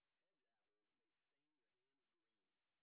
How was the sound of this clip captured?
sp08_white_snr20.wav